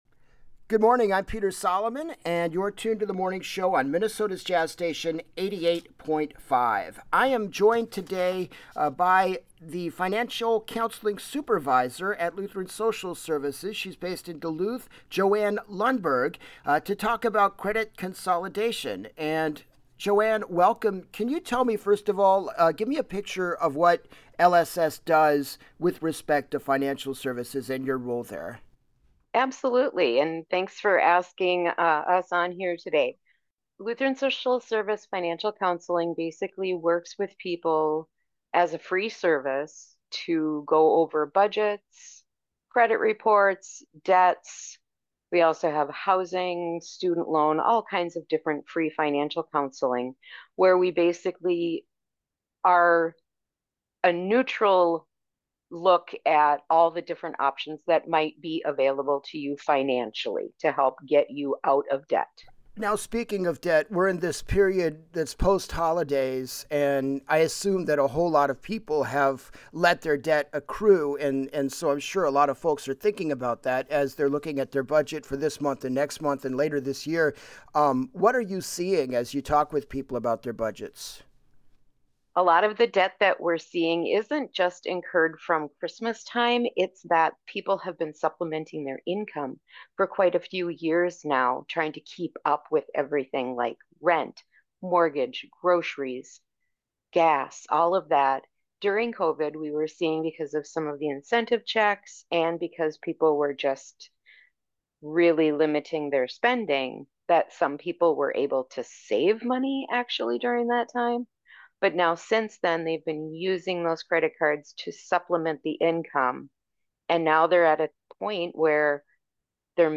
Interviews and features heard during The Morning Show, weekdays from 6 to 10 am.